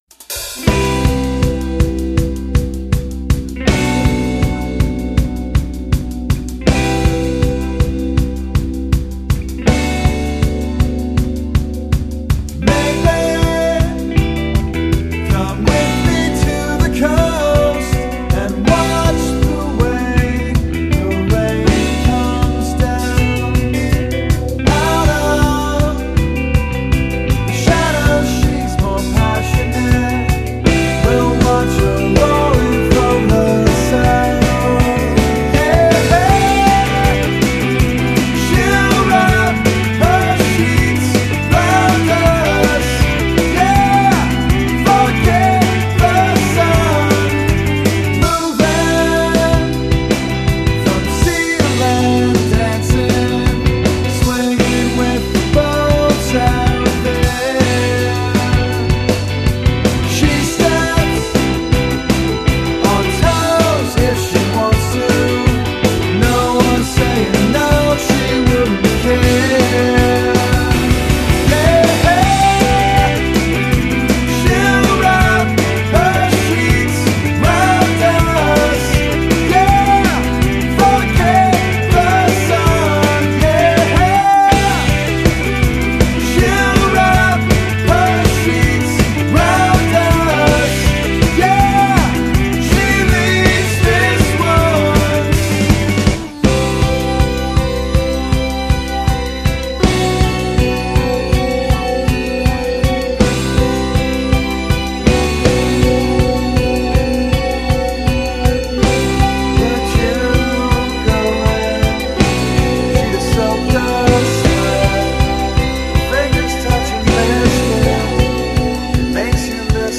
works within a more structured rock format